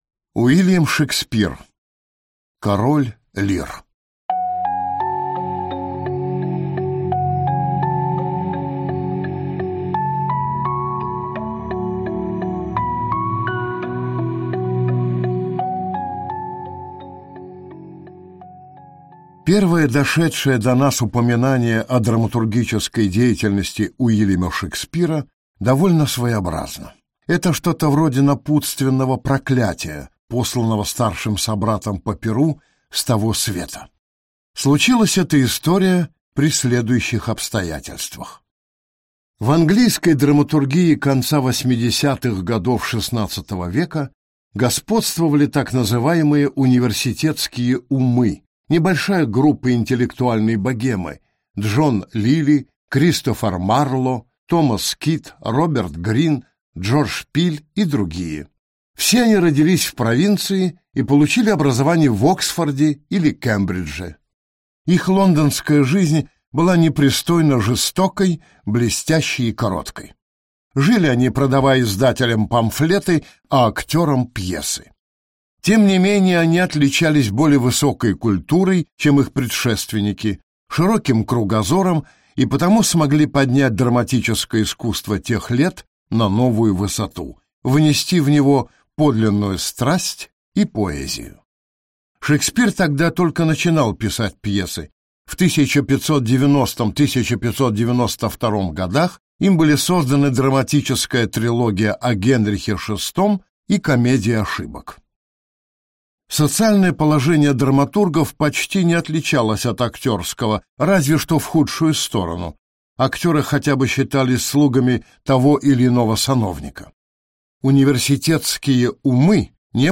Аудиокнига Король Лир | Библиотека аудиокниг